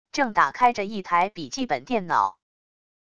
正打开着一台笔记本电脑wav音频